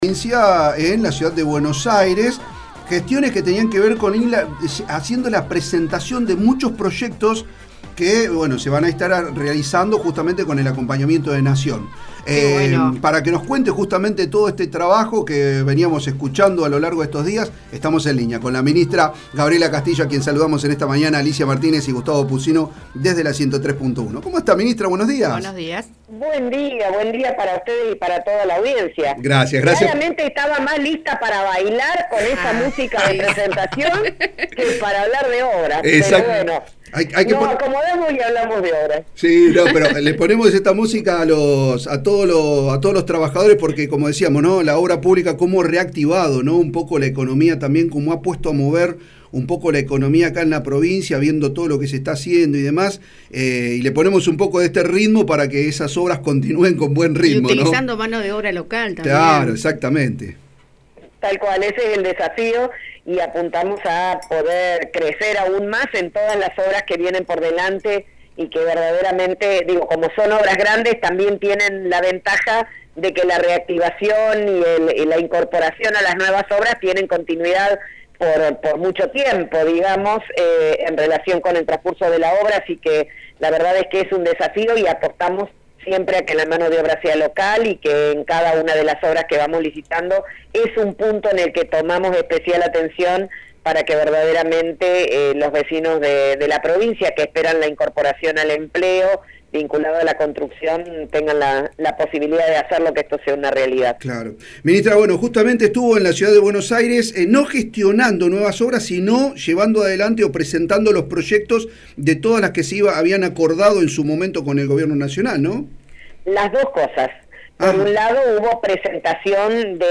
La Ministra de Obras y Servicios Públicos de la Provincia, Gabriela Castillo, habló en la 103.1, la radio pública fueguina, y destacó las diversas gestiones que se realizaron ante el Gobierno Nacional para presentar los proyectos técnicos de obras ya acordadas y presentar propuestas de nuevas obras de acuerdo a las necesidades del gobierno fueguino.